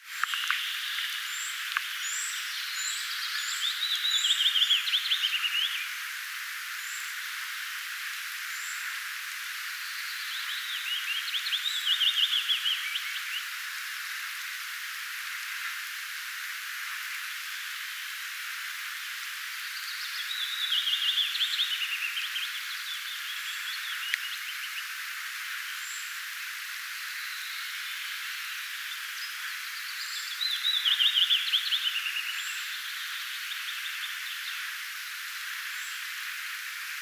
tuollaista puukiipijälinnun
ääntelyä jo kesällä!
tuollaista_puukiipijalinnun_aantelya_jo_kesalla.mp3